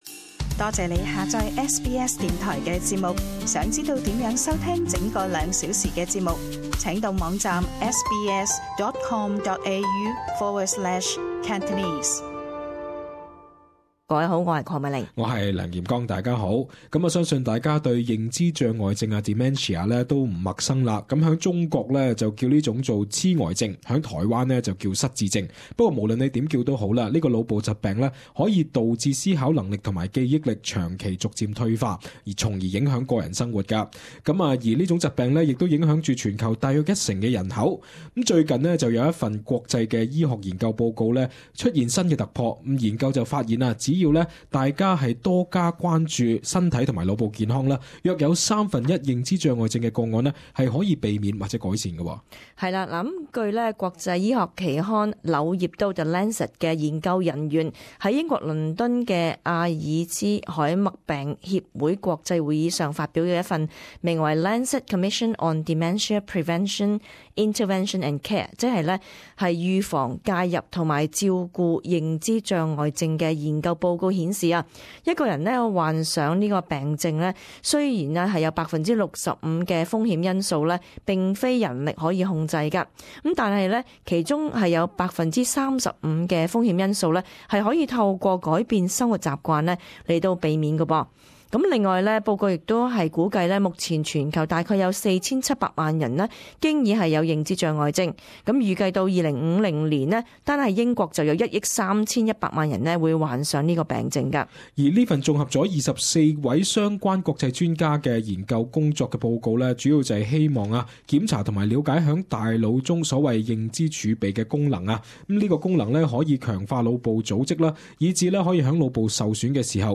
【時事報導】研究：改變生活方式可減低「認知障礙症」風險